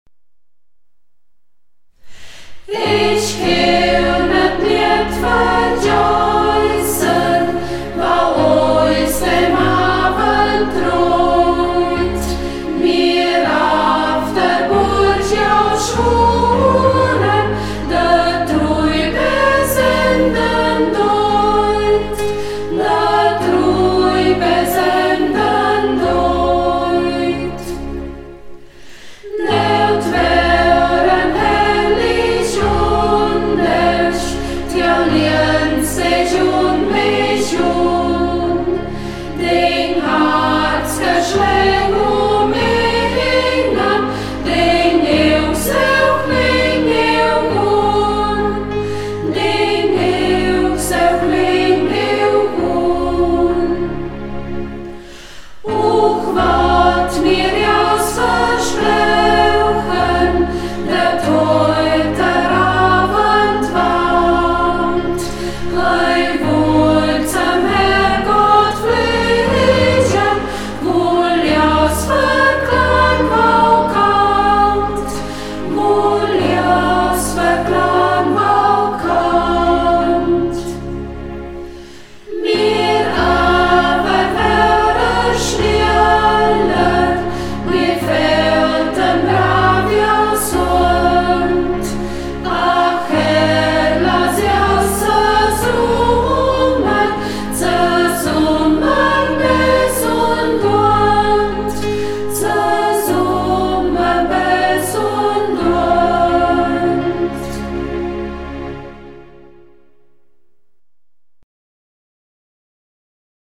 Burgberger Chor • Ortsmundart: Burgberg • 1:53 Minuten • Herunterladen